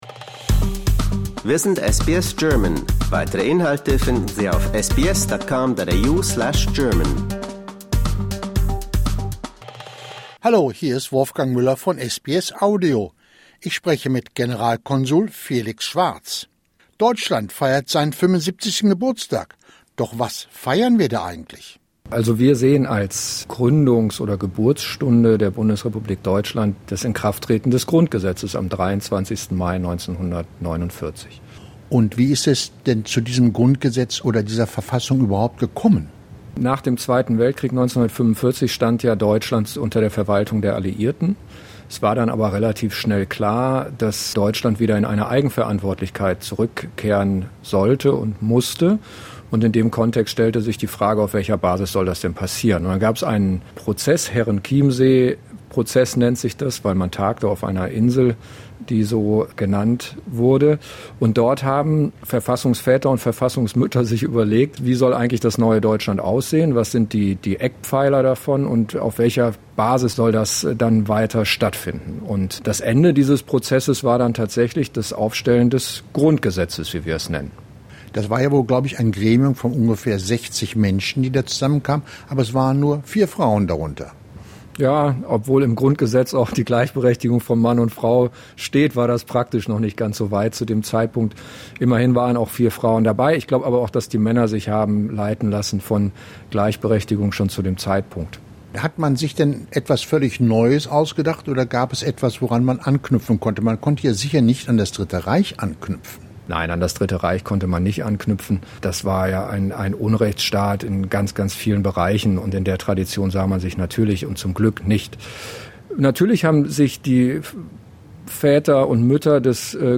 Consul General Felix Schwarz explains the significance of the Basic Law for the past three-quarters of a century of German history.